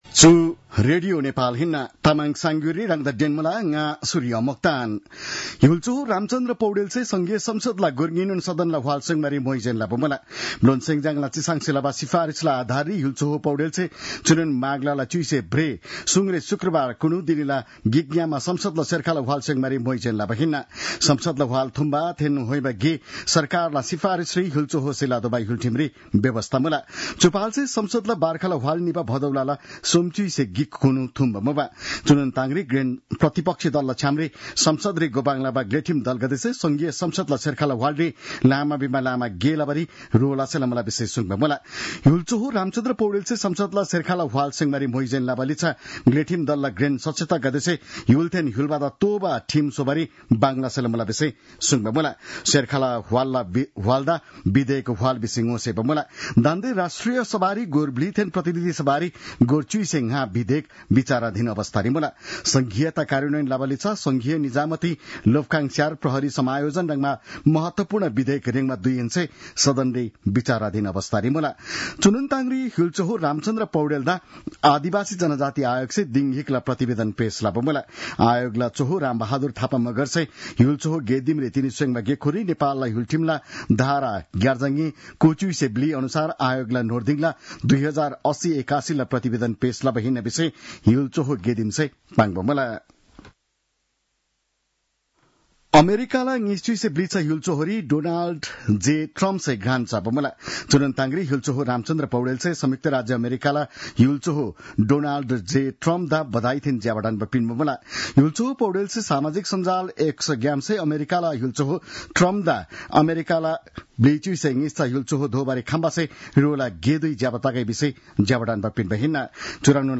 An online outlet of Nepal's national radio broadcaster
तामाङ भाषाको समाचार : ९ माघ , २०८१